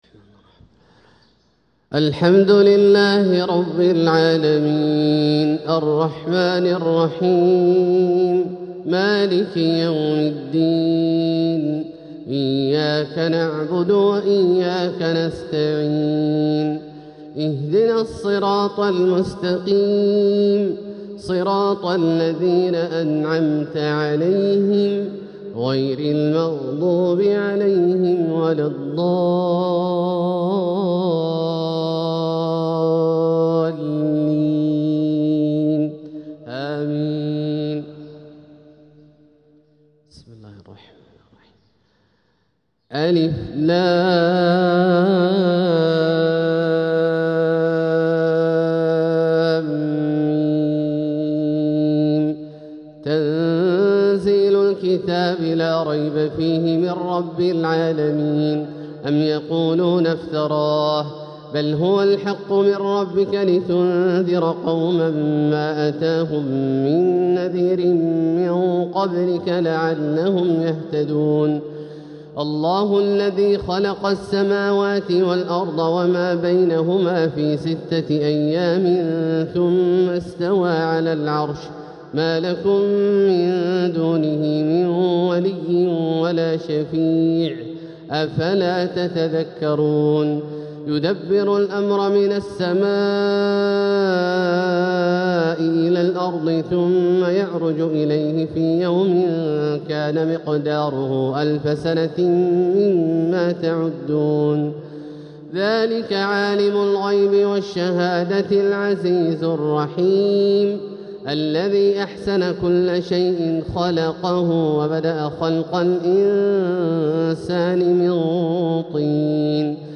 تلاوة لسورتي السجدة و الإنسان | فجر الجمعة 7 صفر 1447هـ > ١٤٤٧هـ > الفروض - تلاوات عبدالله الجهني